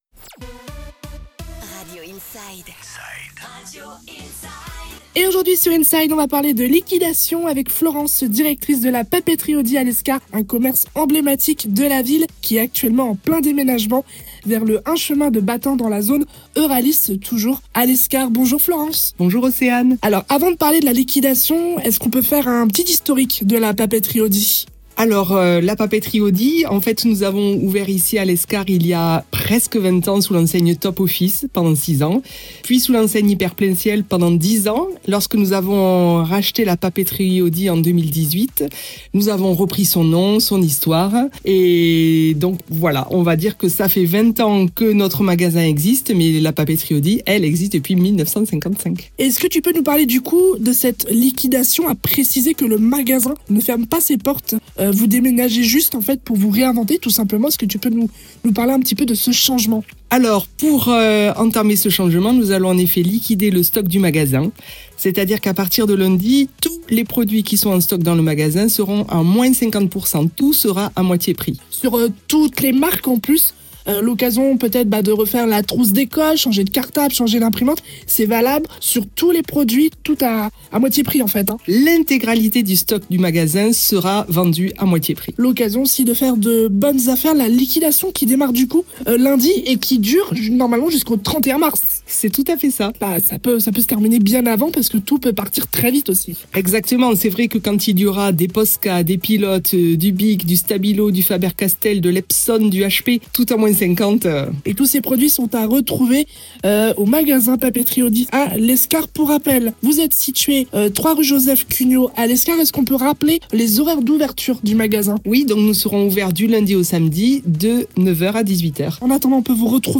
INSIDE : Réécoutez les flash infos et les différentes chroniques de votre radio⬦